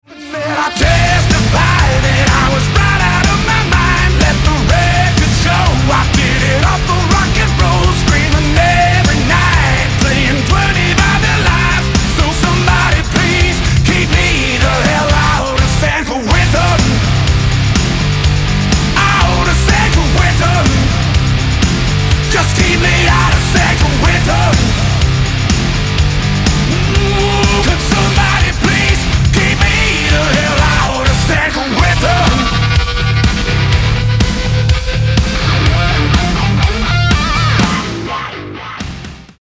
alternative rock , мужской голос , post-grunge , драйвовые